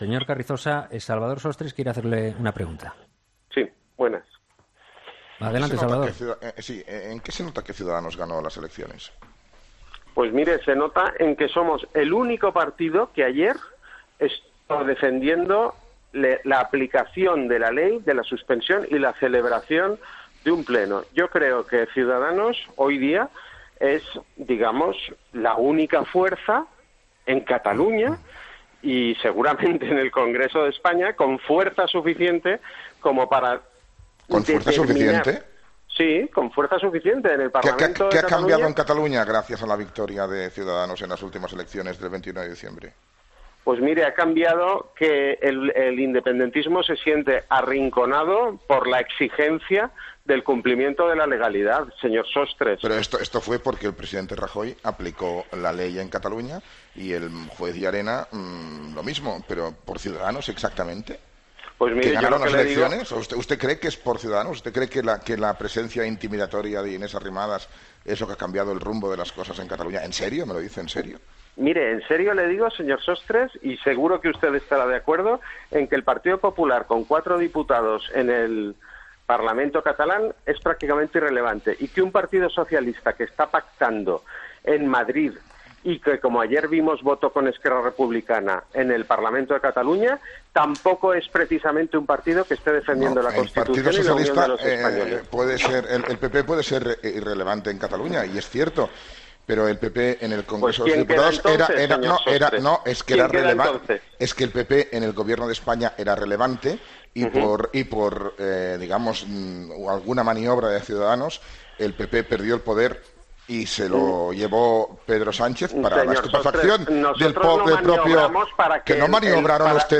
El diputado por Ciudadanos en el parlamento de Cataluña Carlos Carrizosa y el tertuliano Salvador Sostres han protagonizado este jueves un duro encontronazo durante la entrevista que el primero ha concedido al programa 'Herrera en COPE'.